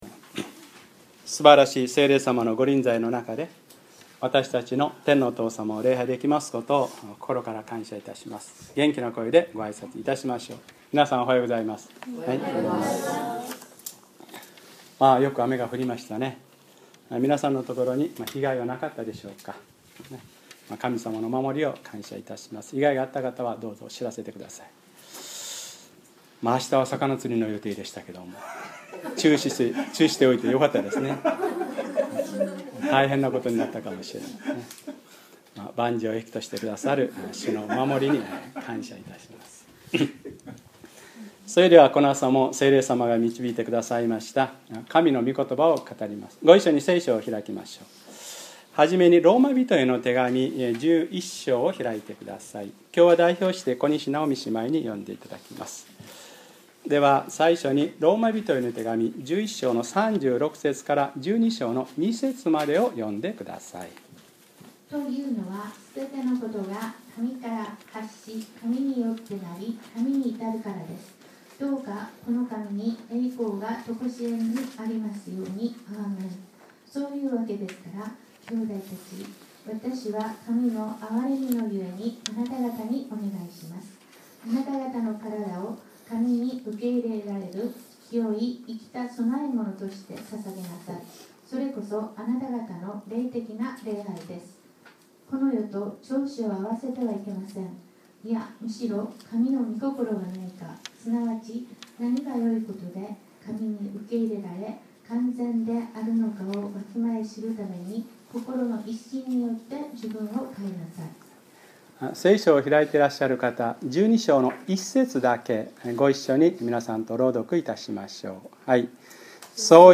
2012年7月15日(日）礼拝説教 『神に受け入れられる聖い生きた供え物』